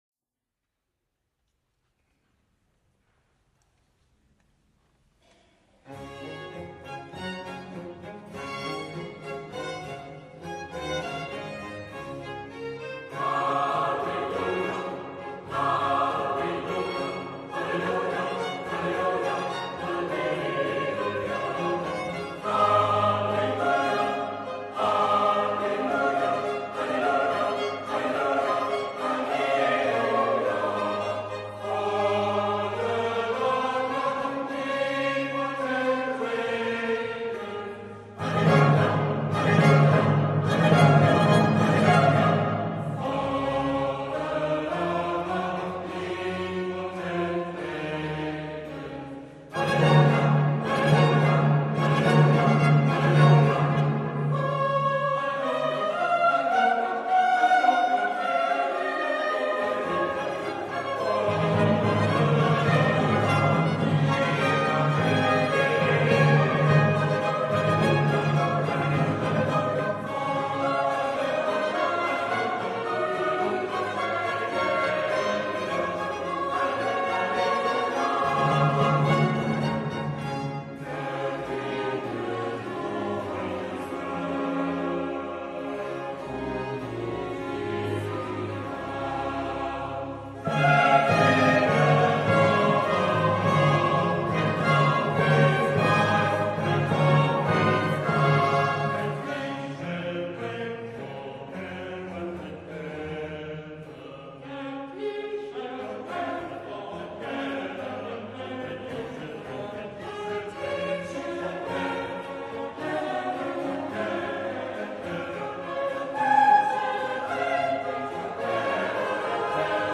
VokalEnsemble St. Marien Neunkirchen
Anspruchsvolle geistliche Chormusik
Die einzelnen Sängerinnen und Sänger des Ensembles sind Laien mit langjähriger Chorerfahrung, Musikerinnen und Musiker mit teilweise (semi-)professioneller Ausbildung und qualifiziertem Stimmbildungsunterricht sowie auch ausgebildete Sängerinnen und Sänger.